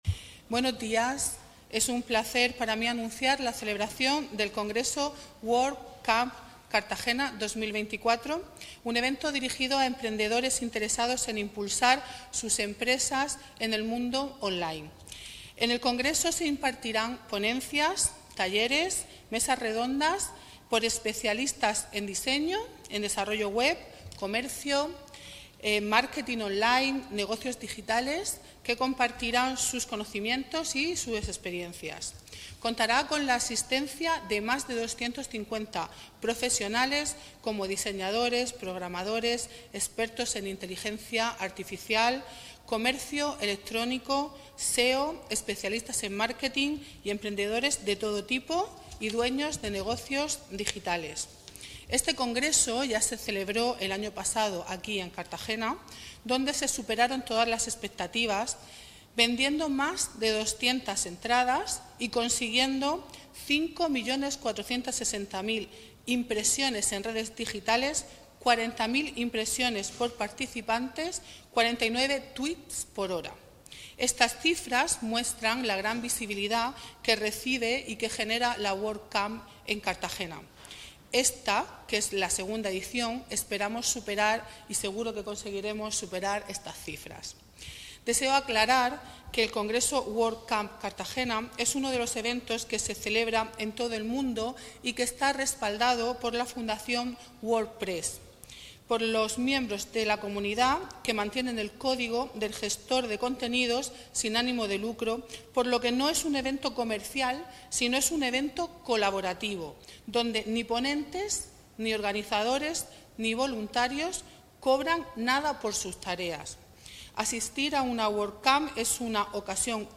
El congreso ha sido presentado en el Palacio Consistorial de Cartagena este lunes, 27 de mayo.